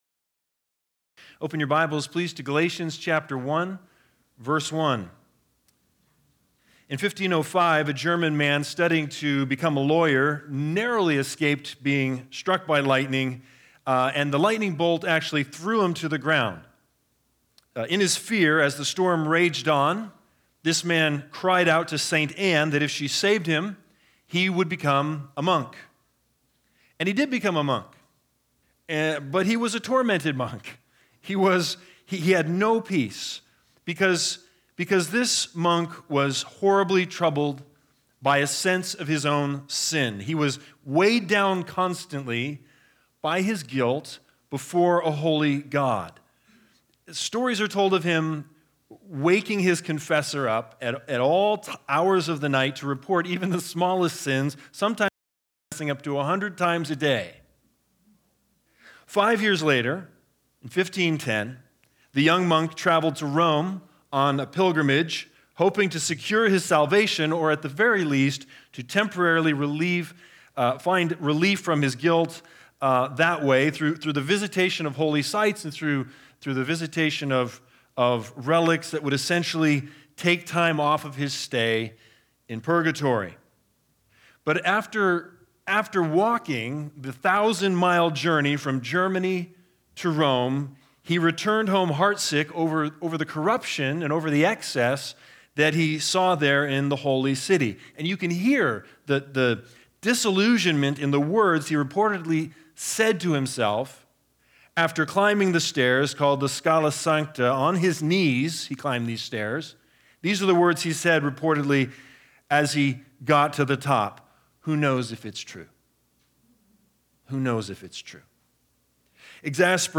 Galatians 1:1-2 Service Type: Sunday Sermons BIG IDEA